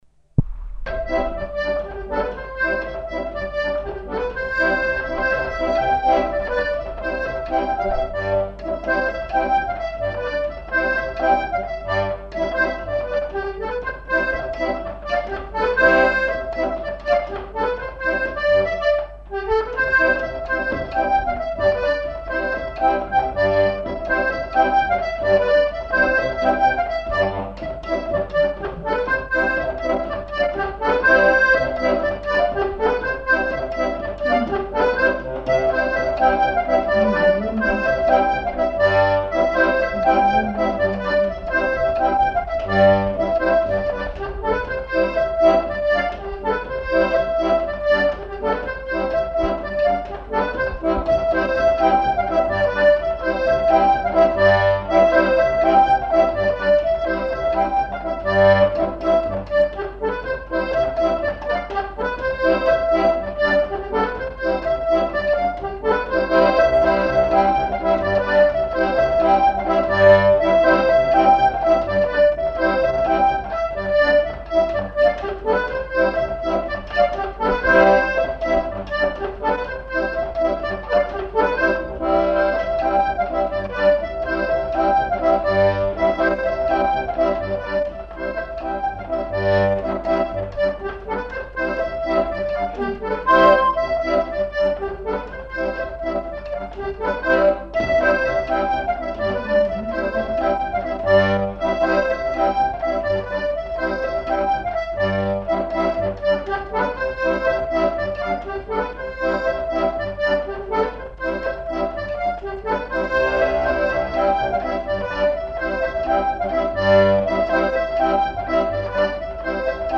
Aire culturelle : Savès
Lieu : [sans lieu] ; Gers
Genre : morceau instrumental
Instrument de musique : accordéon diatonique
Danse : rondeau